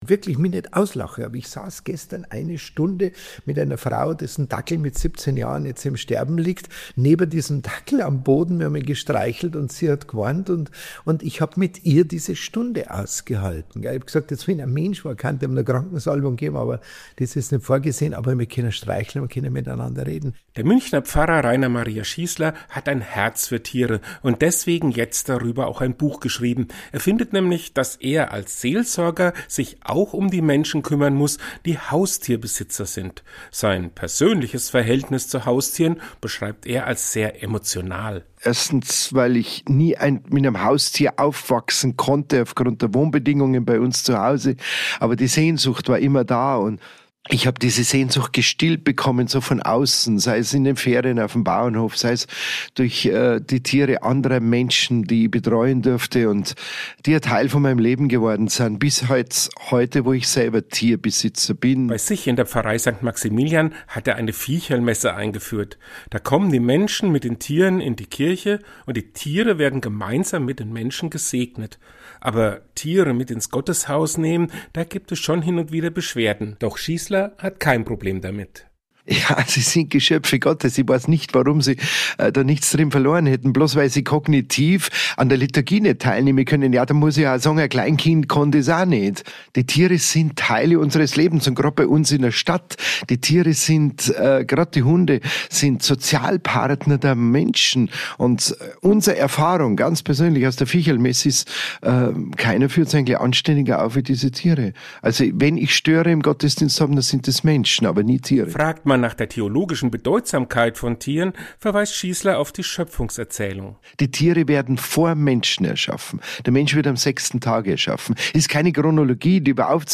hat mit ihm darüber gesprochen.